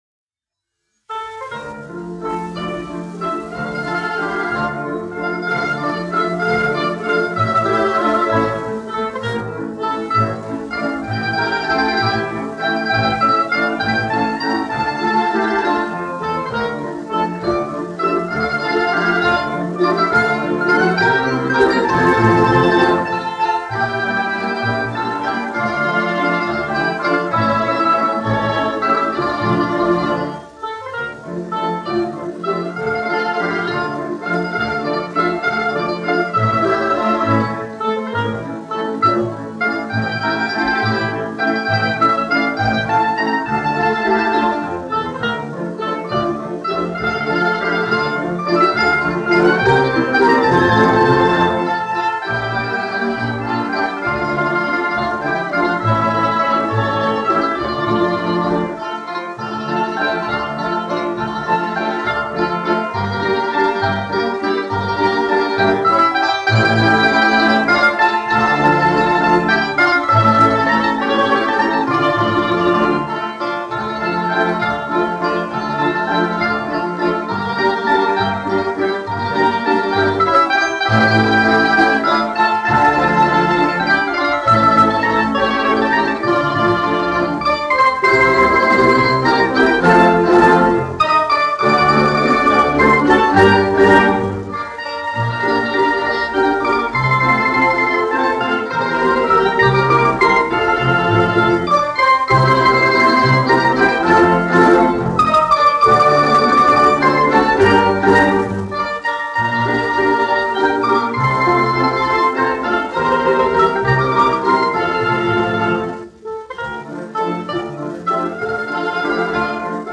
1 skpl. : analogs, 78 apgr/min, mono ; 25 cm
Valši
Balalaiku orķestra mūzika, aranžējumi
Skaņuplate